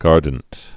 (gärdnt)